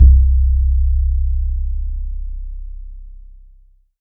BODY BASS 1.wav